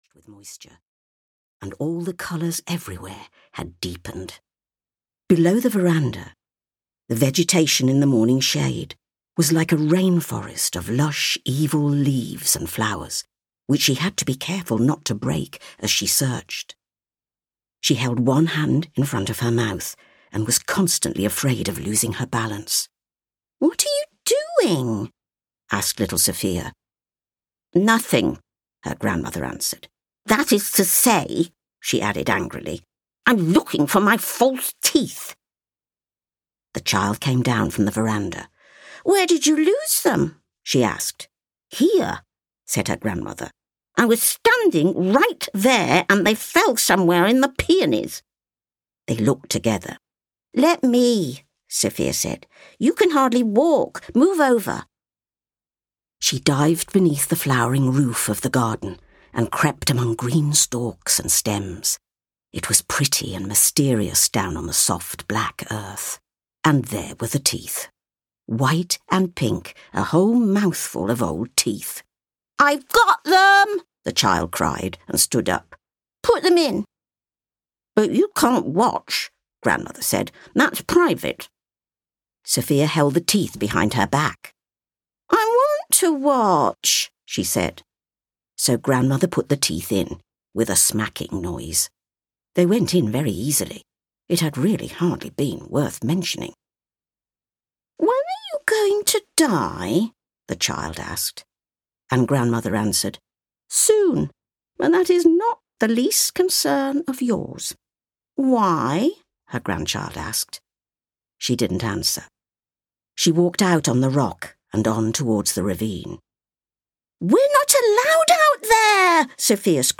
The Summer Book (EN) audiokniha
Ukázka z knihy
• InterpretJulie Walters